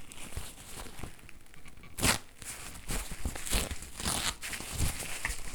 Rasgando y arrugando tela
Grabación sonora del sonido producido por el rasgado y arrugado de una tela
Sonidos: Acciones humanas